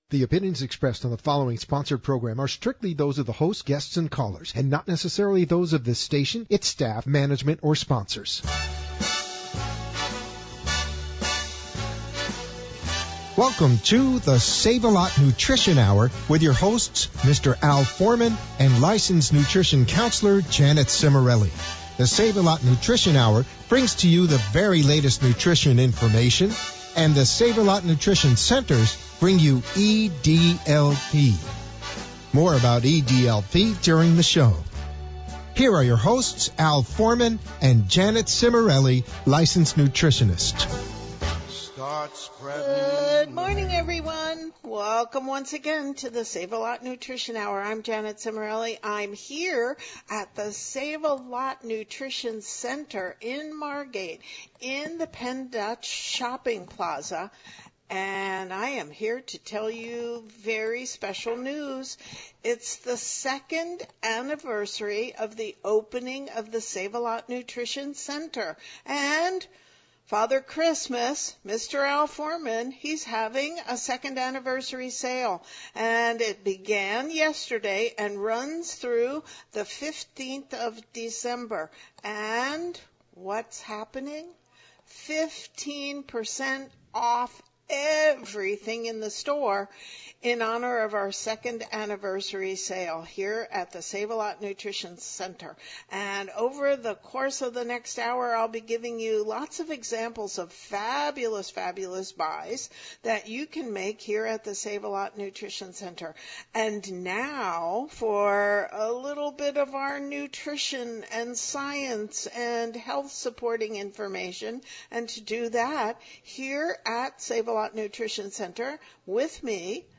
Sav-a-Lot Nutrition Hour on WWNN 1470 AM December 7th 2018